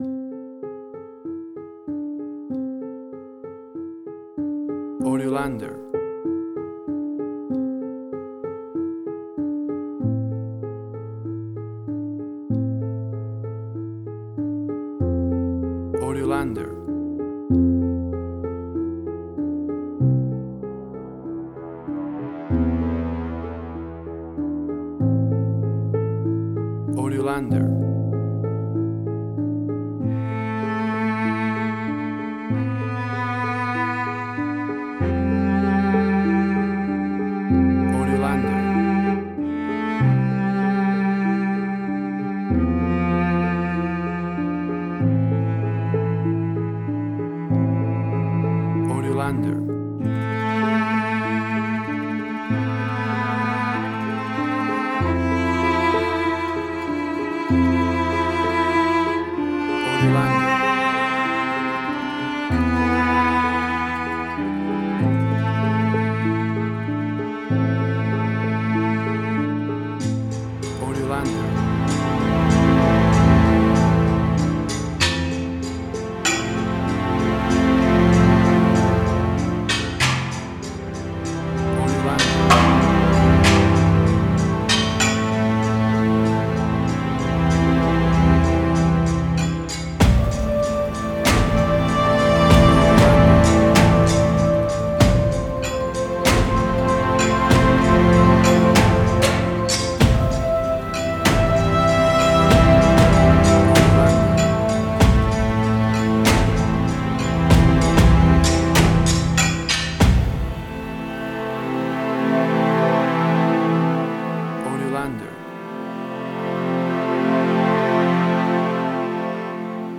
Original Gangster Score
cinematic
Tempo (BPM): 96